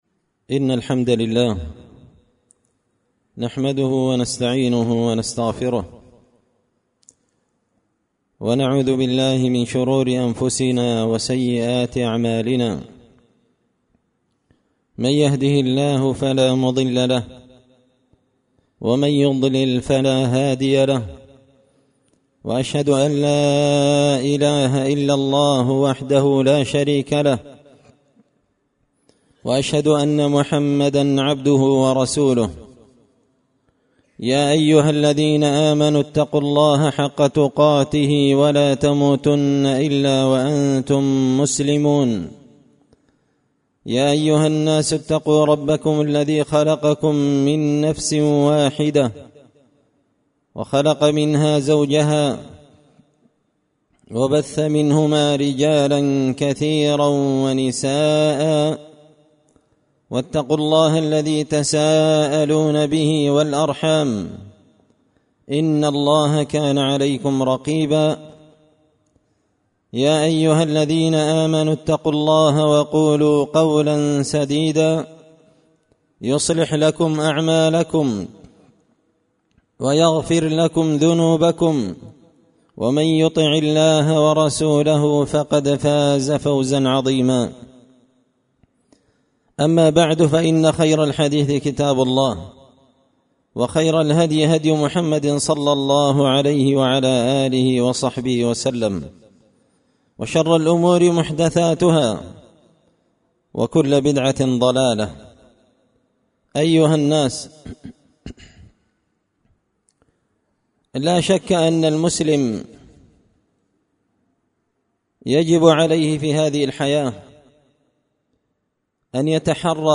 خطبة جمعة بعنوان – التنبيهات على بعض المخالفات في البيوع والمعاملات
دار الحديث بمسجد الفرقان ـ قشن ـ المهرة ـ اليمن